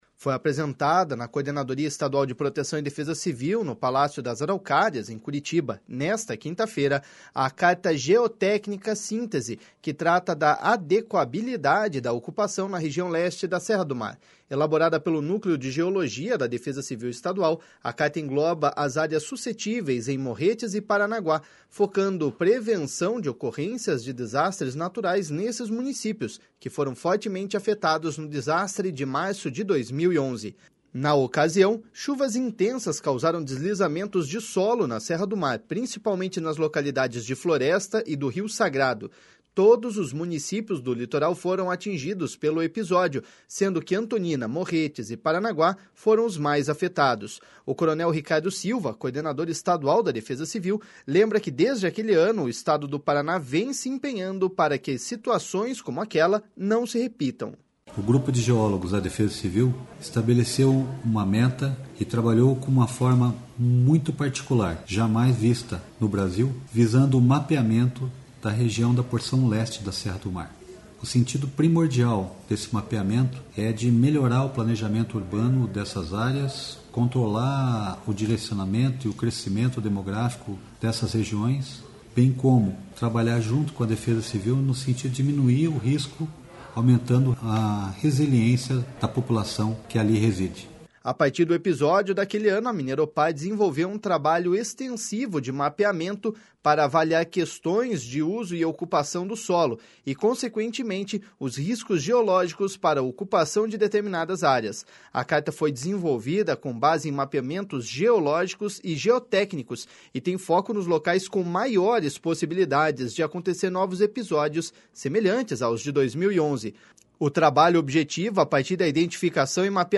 O coronel Ricardo Silva, coordenador Estadual da Defesa Civil, lembra que desde aquele ano, o Estado do Paraná vem se empenhando para que as situações como aquela não se repitam.// SONORA CORONEL RICARDO.//